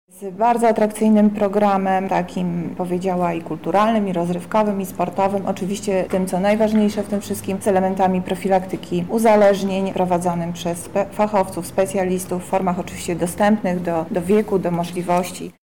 O szczegółach mówi -mówi Monika Lipińska, zastępca prezydenta Lublina